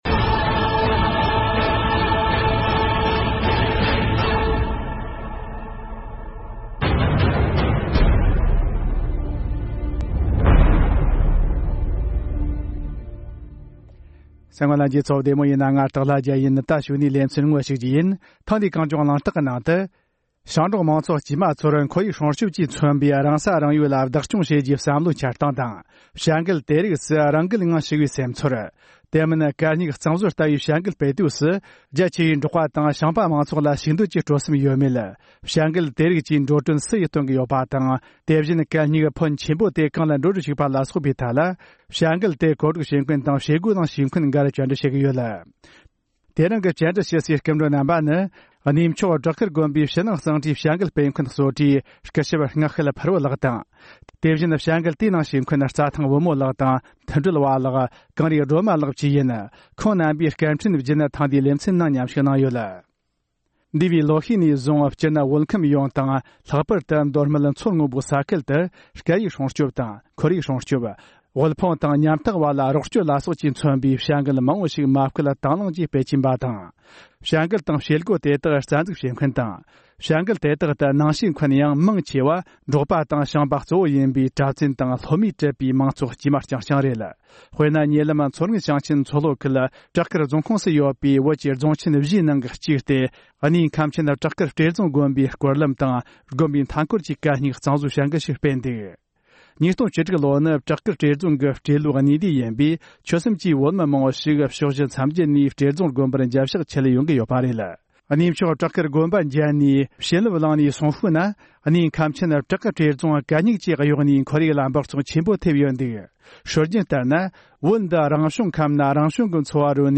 བྱ་འགུལ་གོ་སྒྲིག་མཁན་དང་ནང་ཞུགས་མཁན་བོད་ནང་གི་བོད་མི་བཞི་དང་མཉམ་དུ་བགྲོ་གླེང་ཞུས་པ།